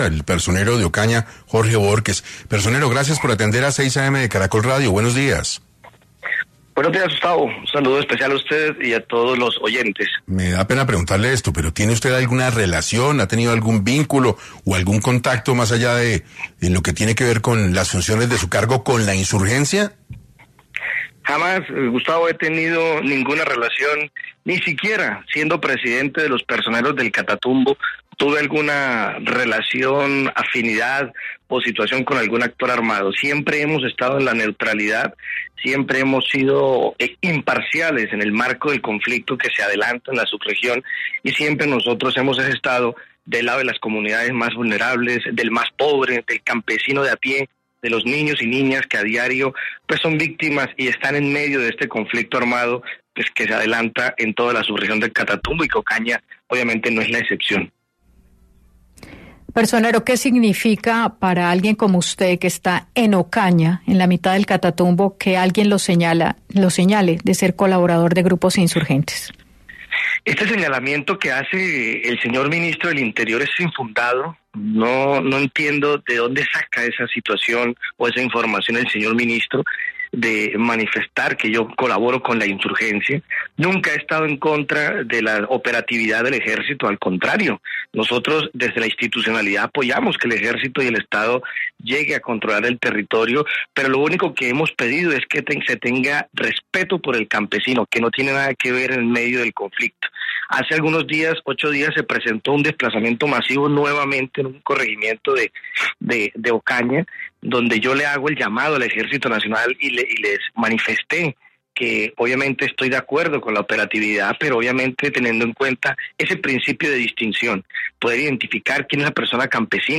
En entrevista para 6AM, Jorge Bohorquez, personero de Ocaña, se expresó sobre los señalamientos hechos por el ministro de interior, Armando Benedetti.